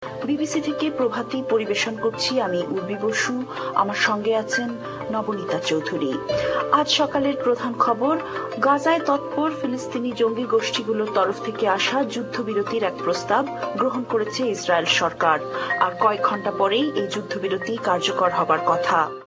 Today’s mystery language comes from an online news bulletin.